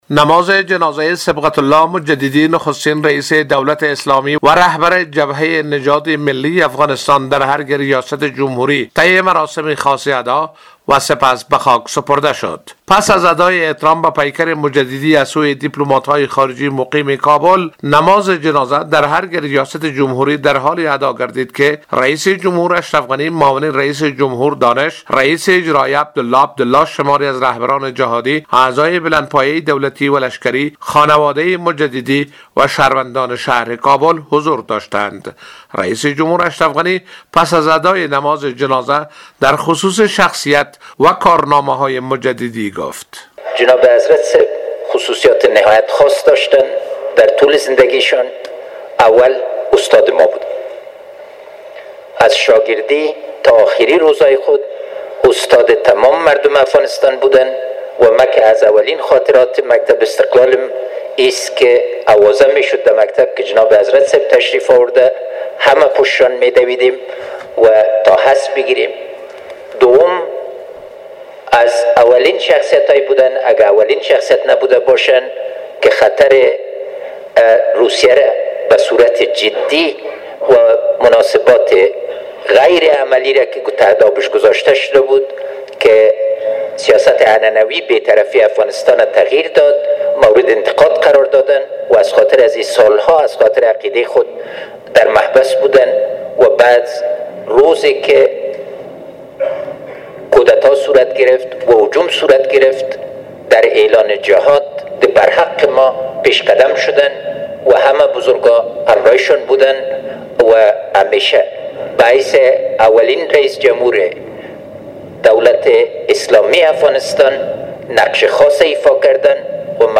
به گزارش خبرنگار رادیو دری،مراسم نماز میت بر پیکر صبغت الله مجددی رییس جمهور اسبق و رهبر حزب نجات ملی افغانستان در ارگ ریاست جمهوری ، برگزار شد.